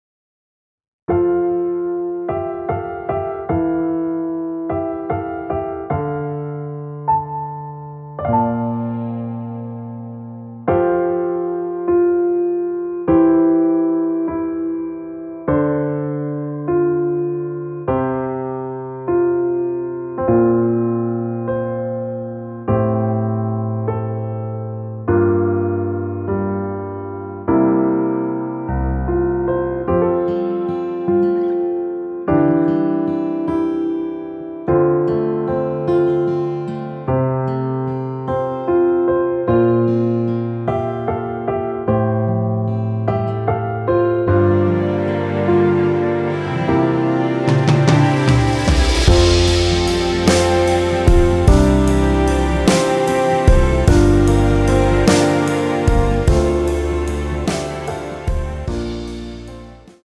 끝없는 밤을 걷자후에 2절의 나의 모든 날들을로 진행이 됩니다.
앞부분30초, 뒷부분30초씩 편집해서 올려 드리고 있습니다.
중간에 음이 끈어지고 다시 나오는 이유는